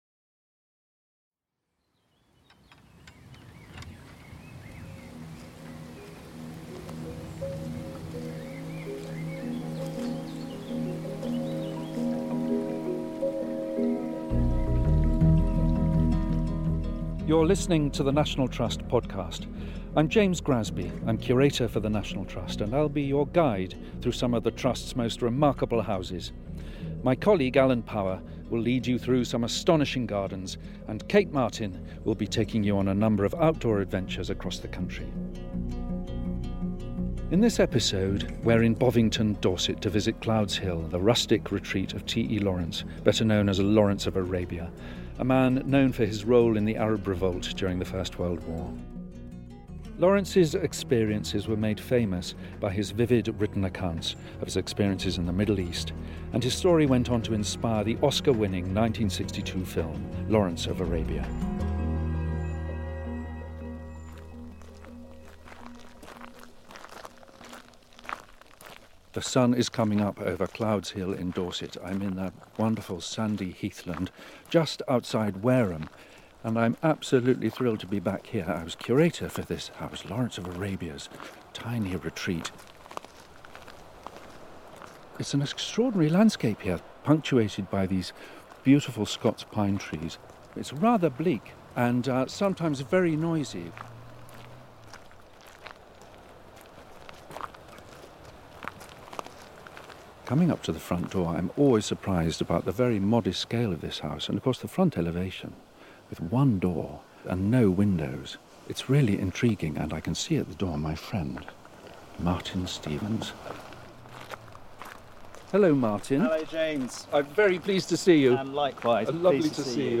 In this episode we’re at Clouds Hill, the rustic retreat of T.E. Lawrence, aka Lawrence of Arabia. We’ll take you on a tour of the intimate spaces where he entertained friends, listened to music, and kept his beloved motorbike.